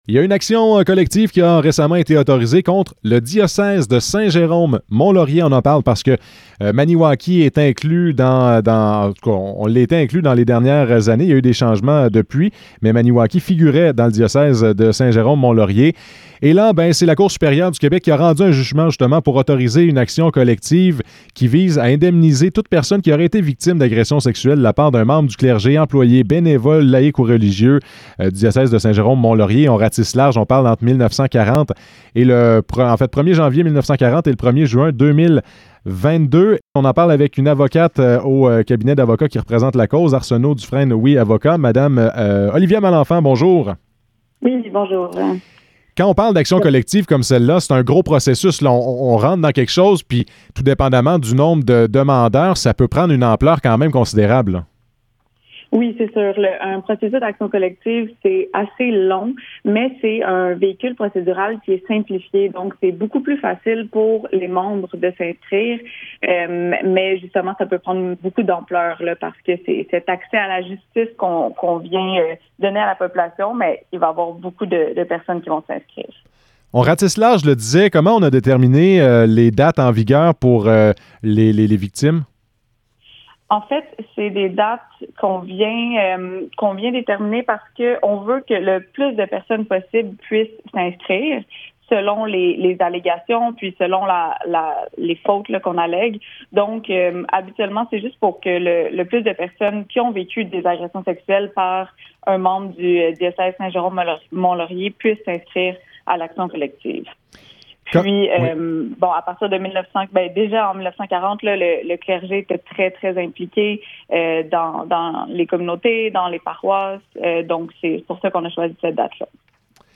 Entrevue sur l'action collective contre le diocèse Saint-Jérôme-Mont-Laurier en lien avec des agressions sexuelles.